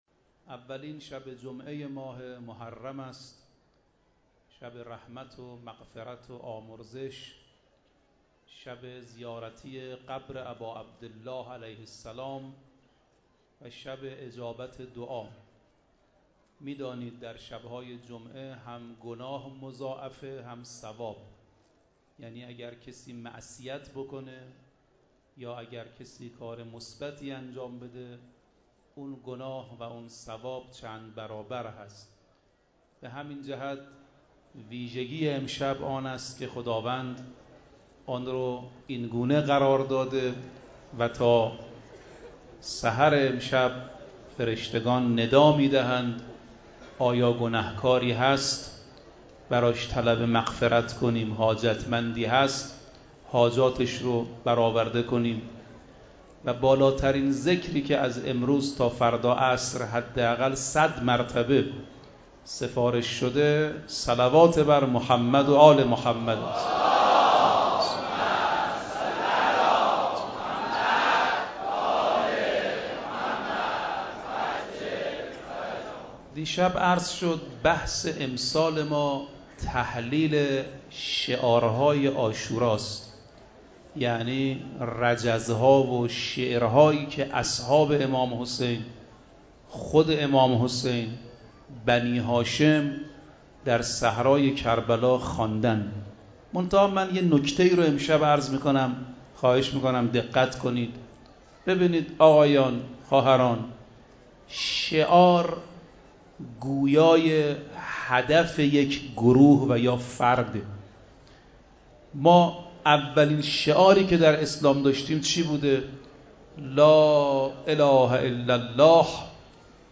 دانلود سخنرانی محرم